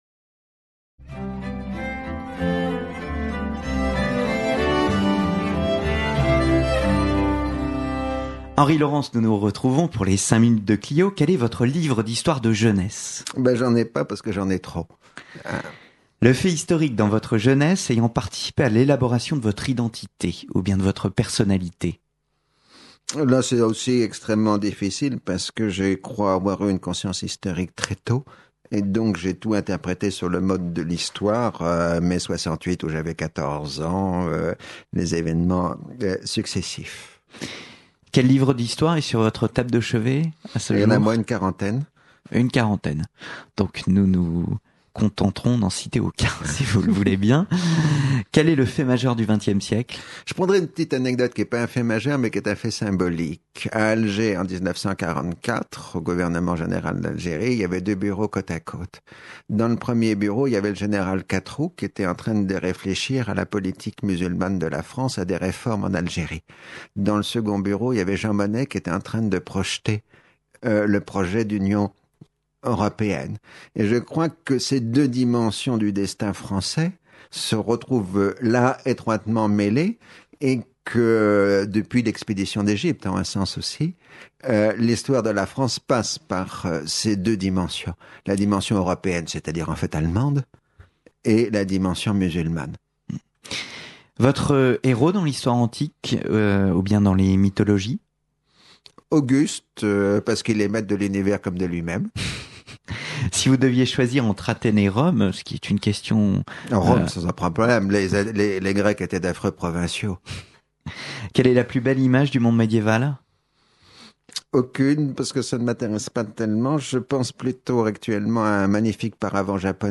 Le sympathique questionnaire historique